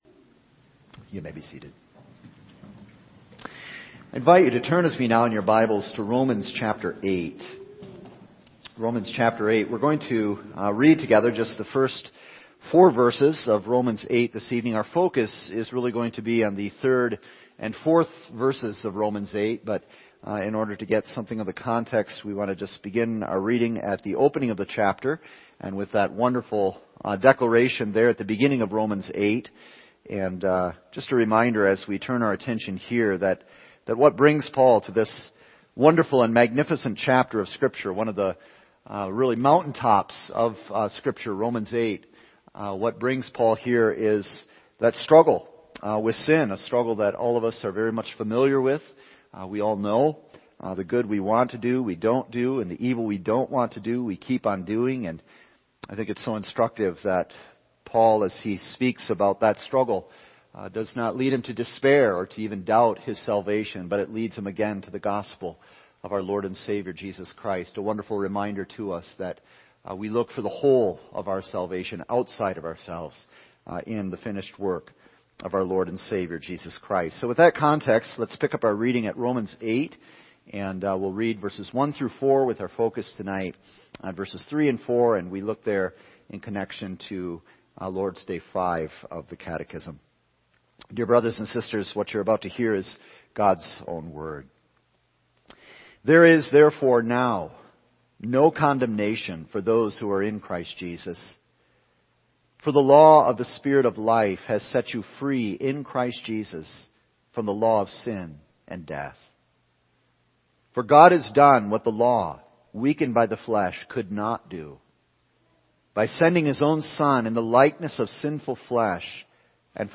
All Sermons What God Did February 27